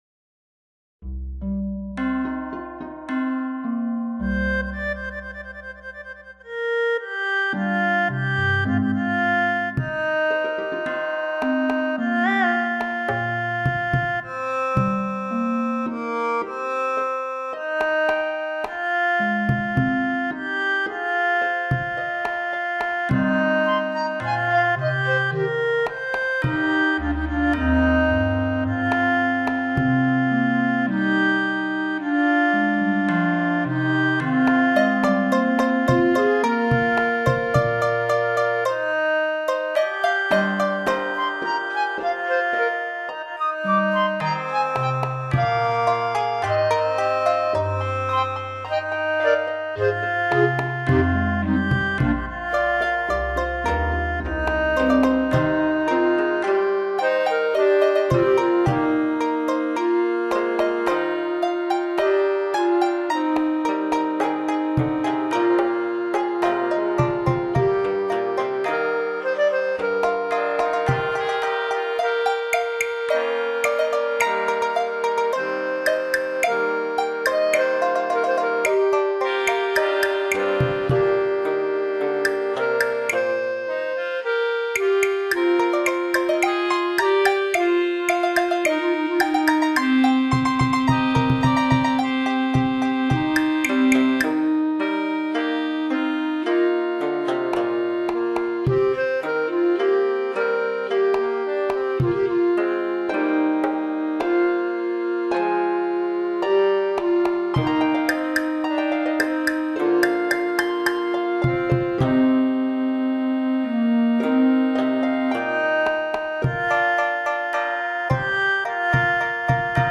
组成八段优雅清新、其音乐富有诗化的内涵，把你带入鸟语花香深林仙境、仿佛席卧于锦锻之上，悠然自得理三焦，